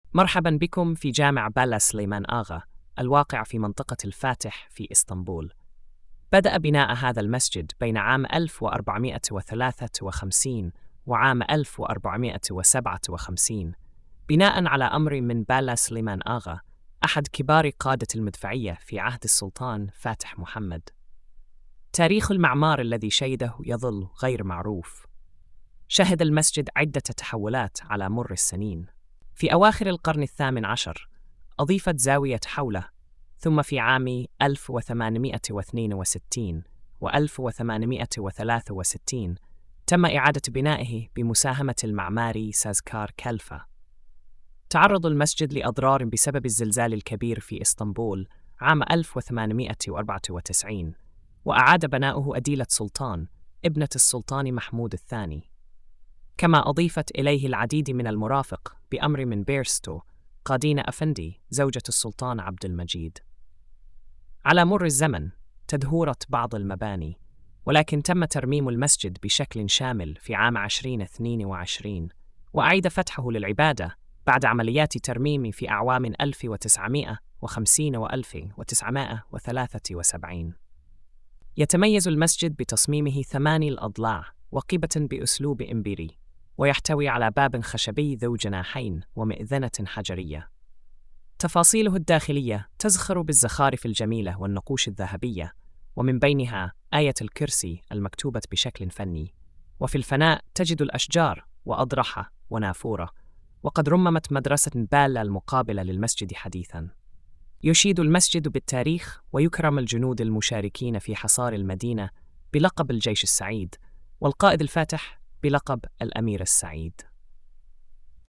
السرد الصوتي